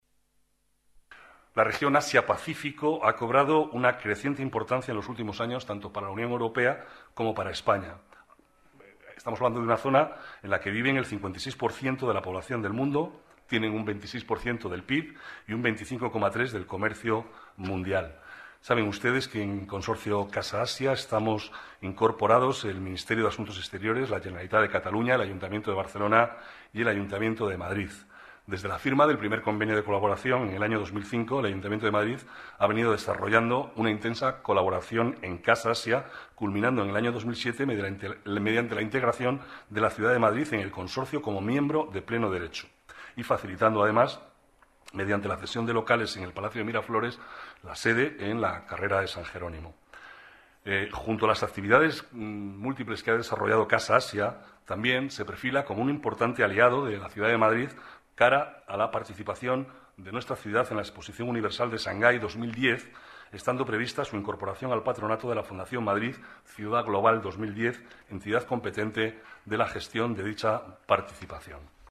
Nueva ventana:Declaraciones del vicealcalde, Manuel Cobo: Renovada la colaboración con Casa Asia